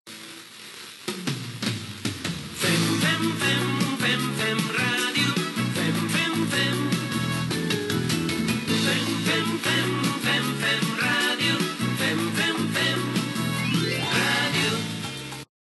Indicatiu cantat de l'emissora